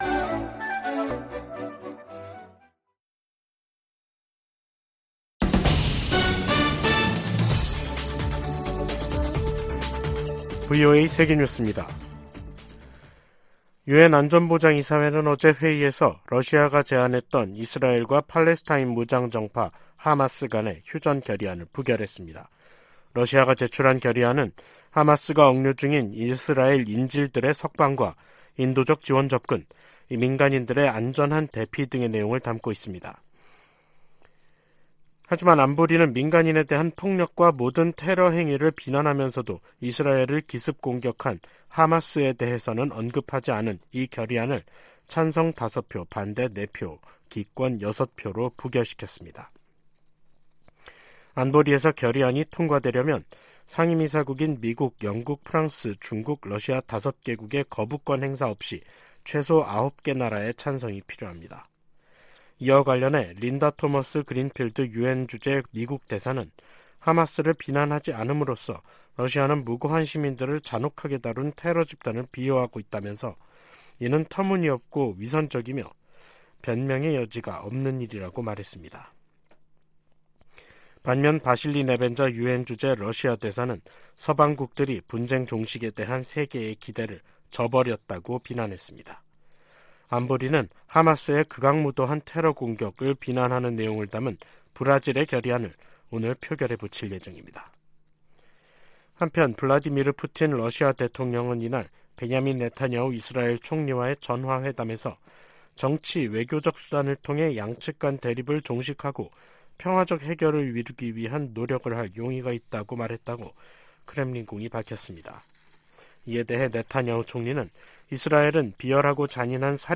VOA 한국어 간판 뉴스 프로그램 '뉴스 투데이', 2023년 10월 17일 2부 방송입니다. 북한-러시아 정상회담에 이어 러시아 외무장관의 방북 계획이 발표되면서 양측 관계가 한층 긴밀해지는 양상입니다. 북한 라진항에서 러시아로 무기를 운송한 화물선이 미국 정부의 제재를 받고 있는 선박으로 나타났습니다. 북한이 미국 본토 미사일 방어망을 무력화시킬 만큼 많은 핵 탑재 대륙간 탄도미사일 배치에 속도를 내고 있다는 미 의회 산하 기구 평가가 나왔습니다.